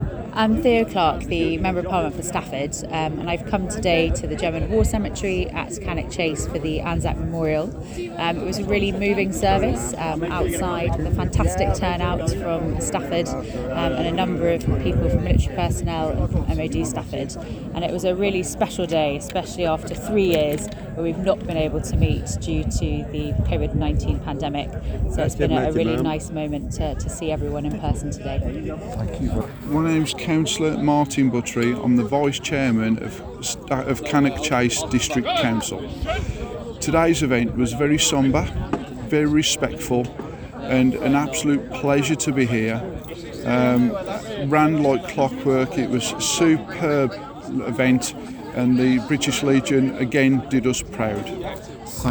Each of the following links connect to audio recordings of some of the many dignitaries who attended the Service:
Member of Parliament for Stafford, Theo Clarke MP
The Vice-Chairman of Cannock Chase Council, Councillor Martyn Buttery